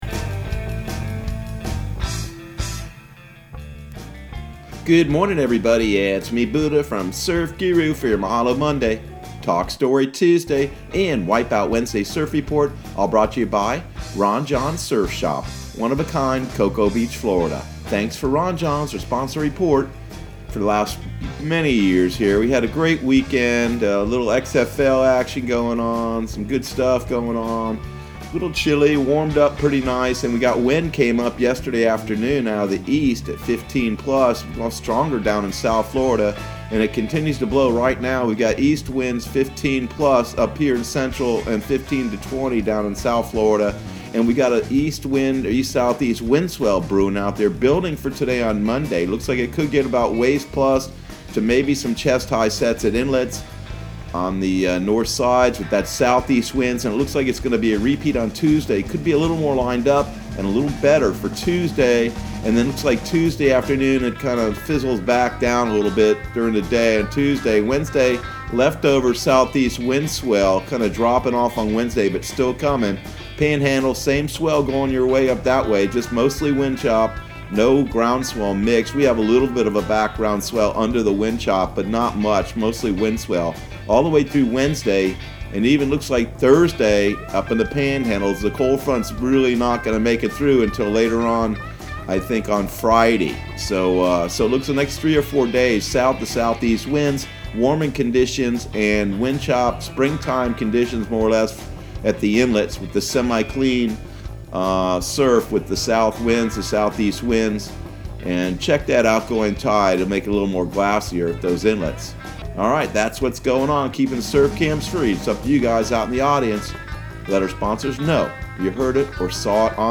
Surf Guru Surf Report and Forecast 02/10/2020 Audio surf report and surf forecast on February 10 for Central Florida and the Southeast.